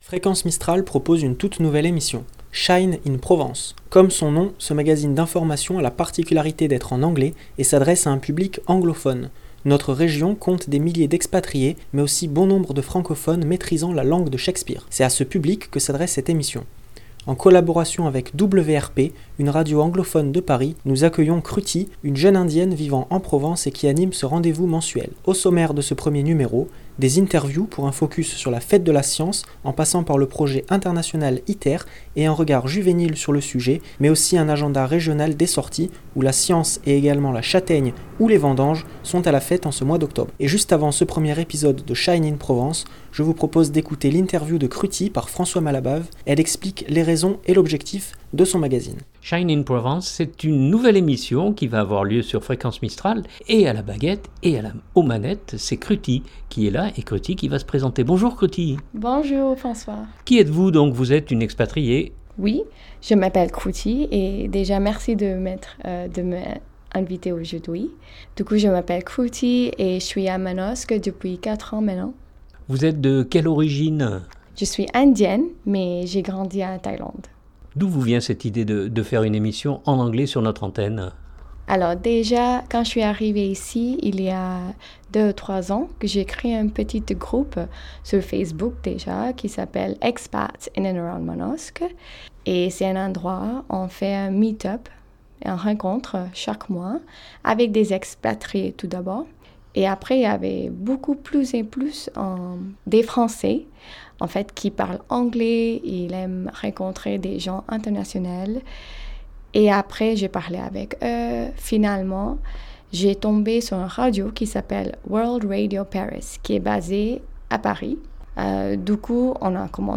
Comme son nom, ce magazine d’information a la particularité d’être en anglais et s’adresse à un public anglophone.